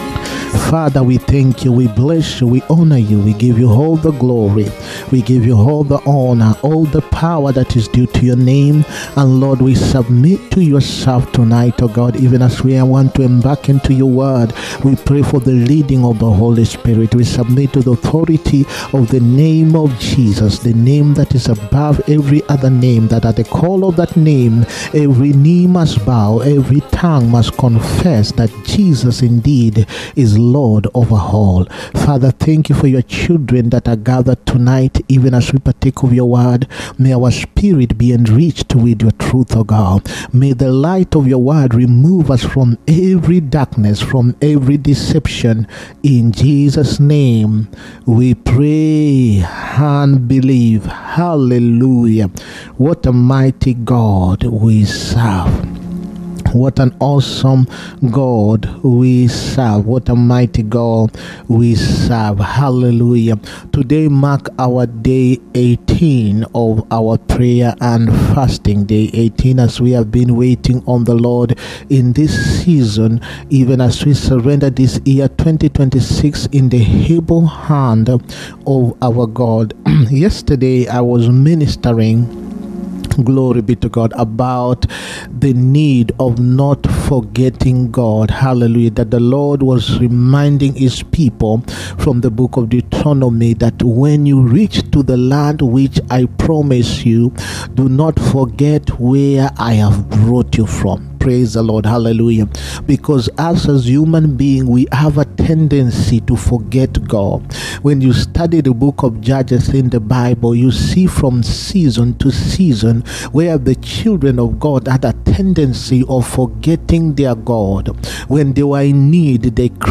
SUNDAY WORSHIP SERVICE. A SEPARATION THAT SAVES. 9TH NOVEMBER 2025.